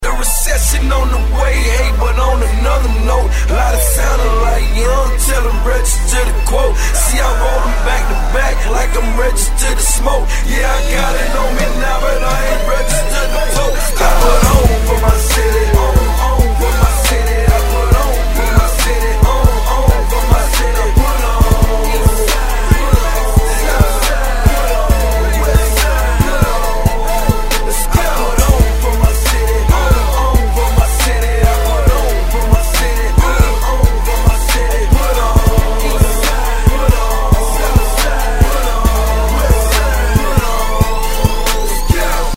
• Качество: 128, Stereo
Хип-хоп
Rap
гангстерские